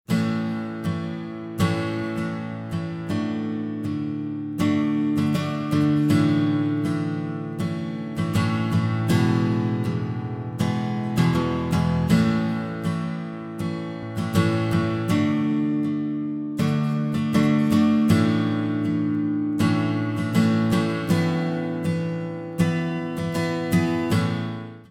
A three-chord song progression
Example-3-Chord-Song-Progression.mp3